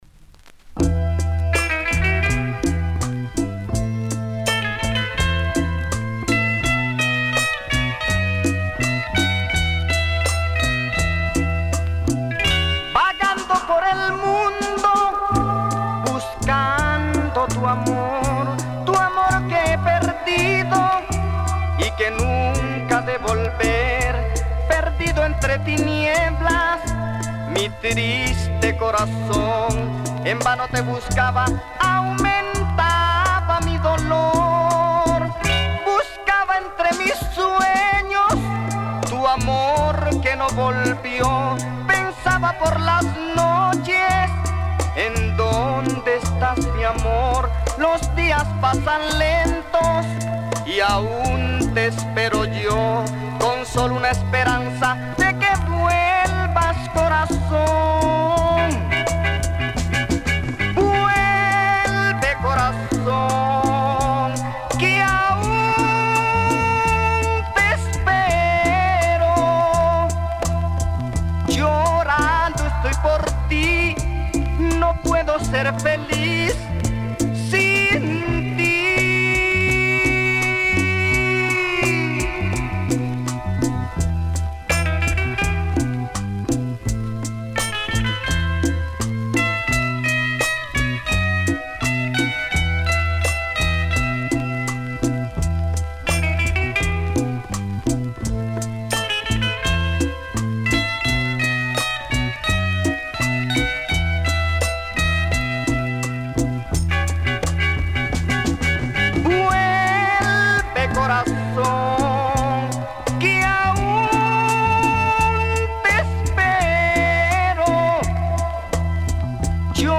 Published September 29, 2010 Boleros y Baladas Comments
over-the-top fuzzed out lyrical romanticism